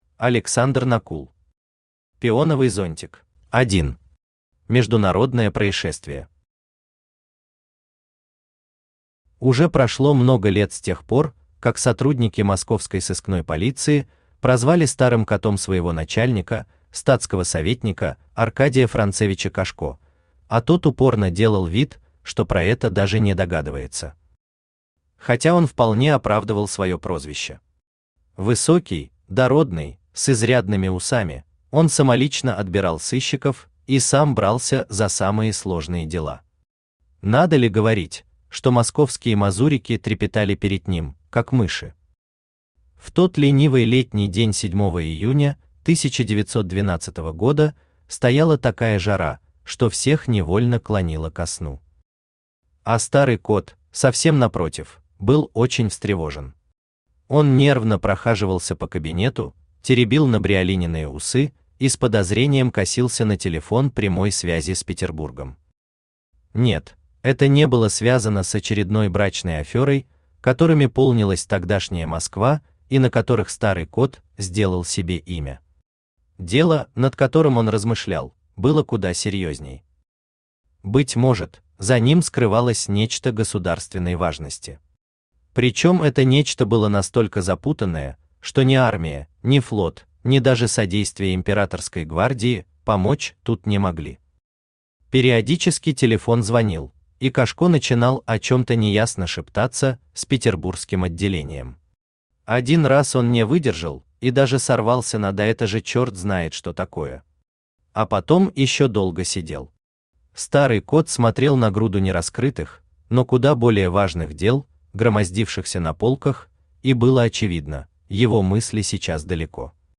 Аудиокнига Пионовый зонтик | Библиотека аудиокниг
Aудиокнига Пионовый зонтик Автор Александр Накул Читает аудиокнигу Авточтец ЛитРес.